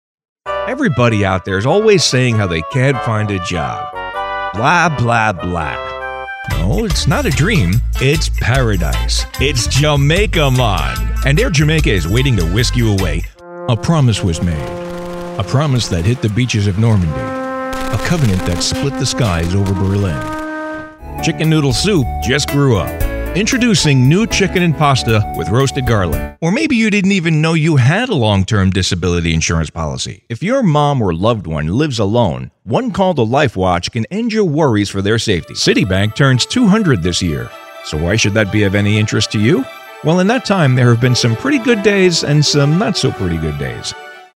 mid-atlantic
Sprechprobe: Werbung (Muttersprache):